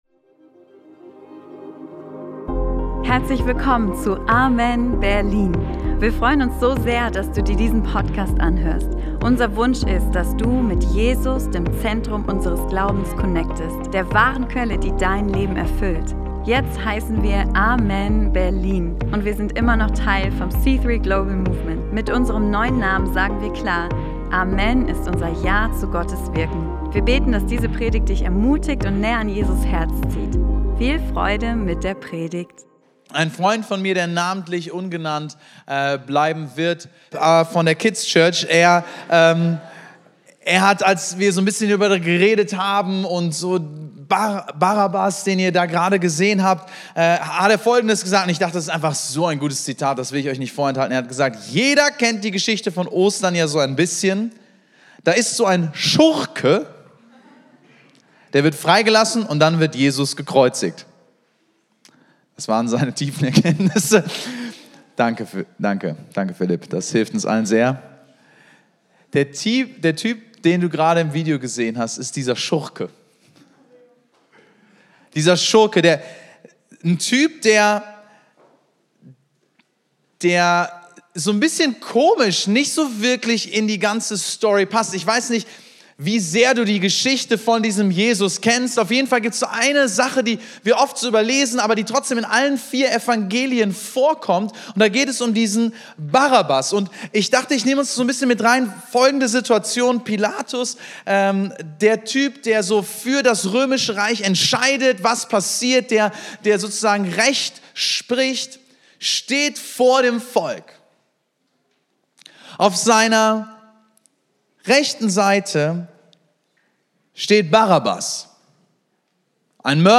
Ich bin Barabbas — Ostersonntag Predigt ~ AMEN Berlin Podcast